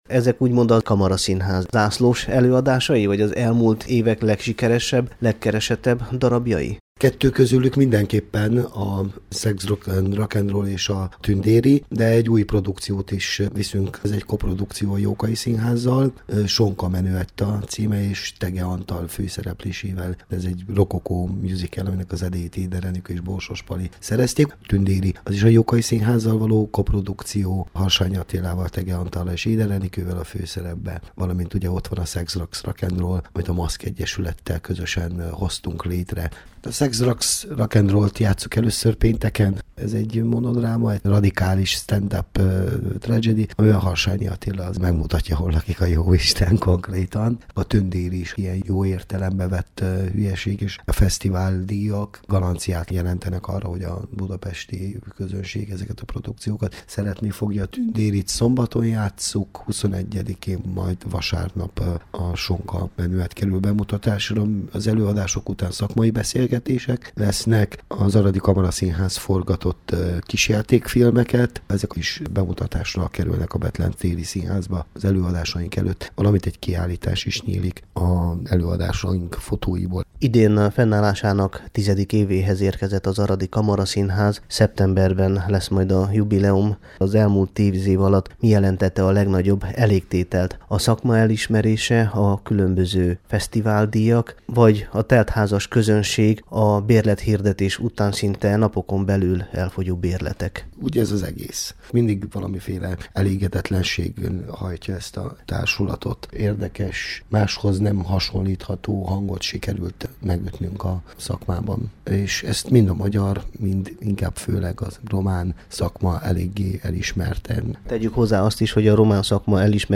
Interjú
vendegvaro_fesztivalon_az_aradi_kamaraszinhaz.mp3